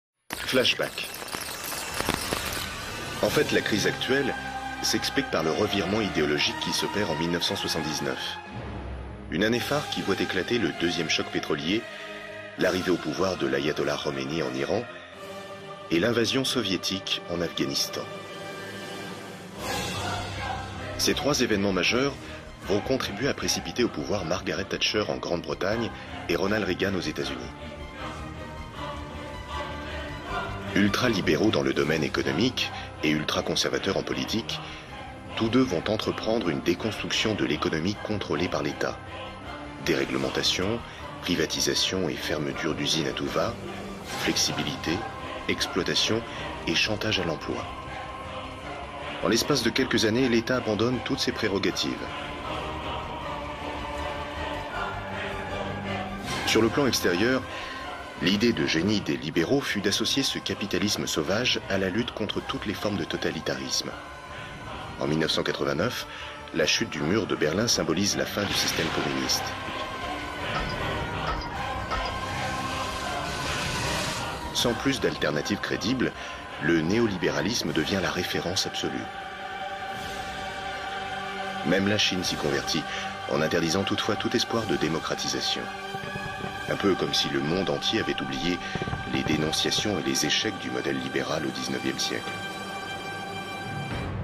Prestation voix-off dans "Krach Connexion" : ton journalistique et naturel
Style "direct", parlé et journalistique.
Voix-off pour le documentaire Krach Connexion diffusé sur LCP.
Dans ce documentaire historique, j’ai opté pour une voix médium grave, à la fois sérieuse et naturelle, dans un style journalistique.